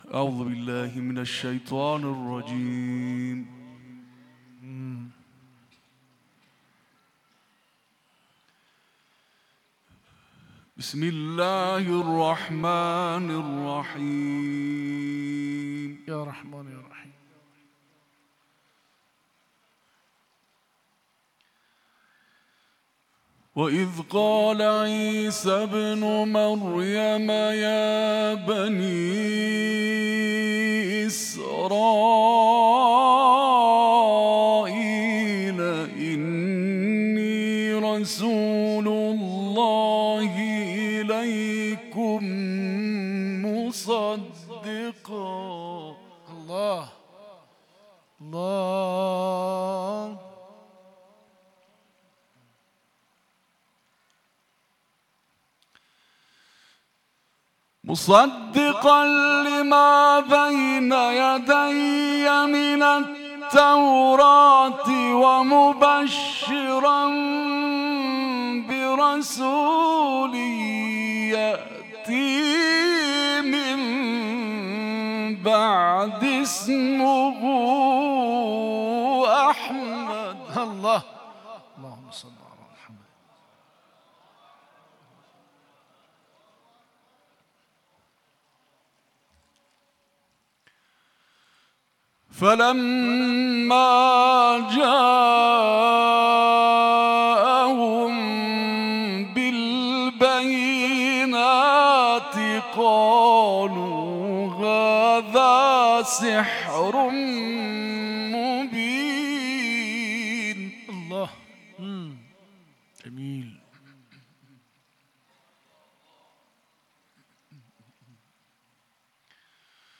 د مشهد اردهال په قرآني محفل کې
بچوې: قرآنی خبرونه ، قرآنی هنرونه ، تلاوت ، قاریان ، حافظان ، آل عمران سوره ، مقاومت ، مقاومتی چورلیز